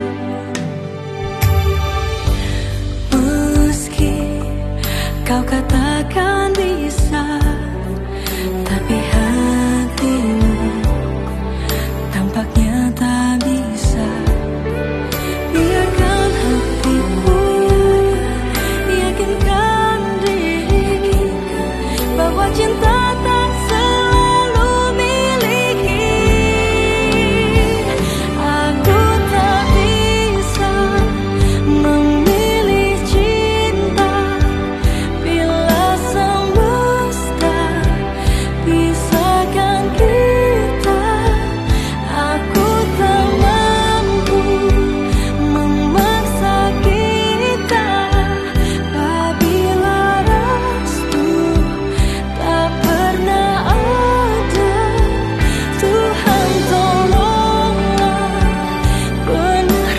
dinyanyikan live pertama kalinya